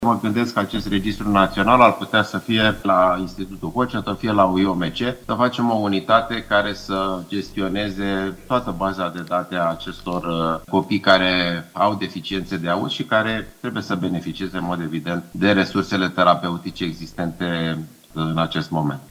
Ministrul Sănătății, Alexandru Rafila, într-o dezbatere organizată de DC News: „Să facem o unitate care să gestioneze toată baza de date a acestor copii care au deficiențe de auz”